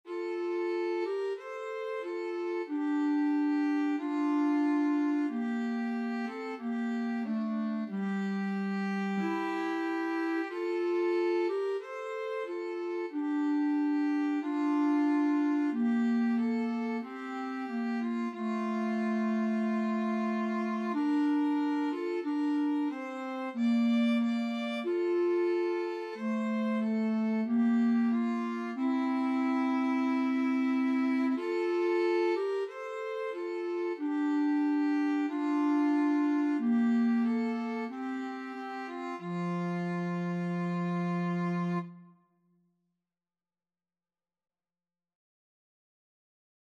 Clarinet-Violin Duet version
Andante = c. 92